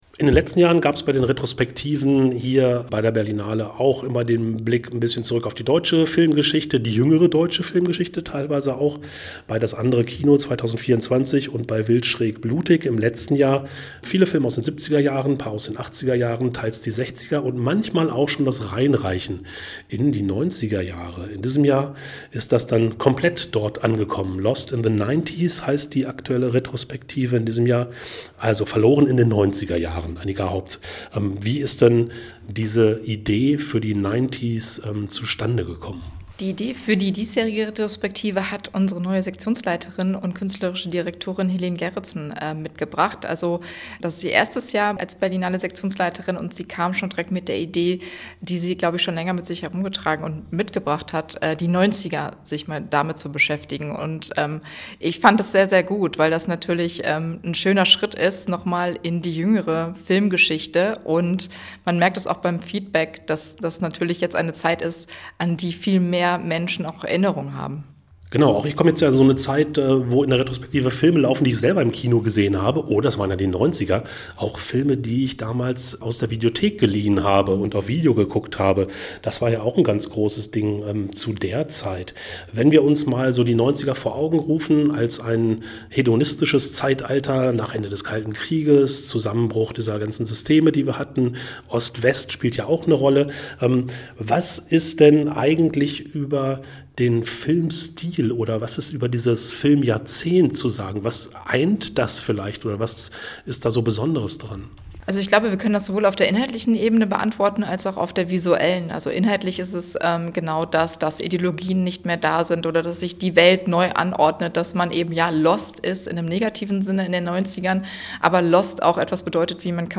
Radiointerview
Berlinale, Filmtexte, Interviews